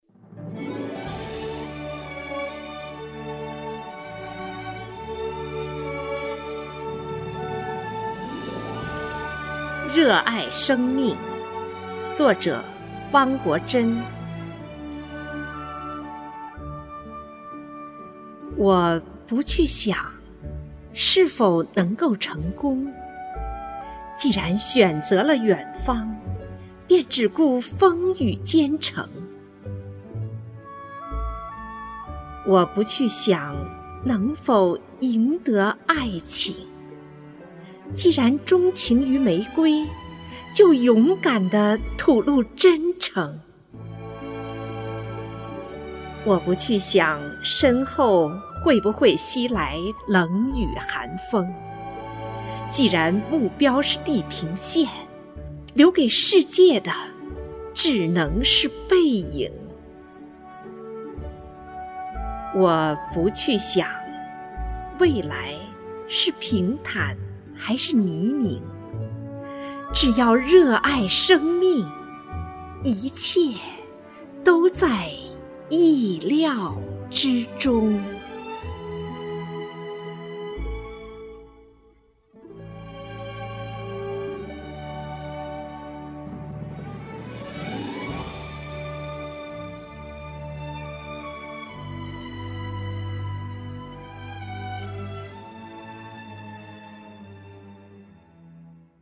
首页 视听 经典朗诵欣赏 网络精选——那些张扬个性的声音魅力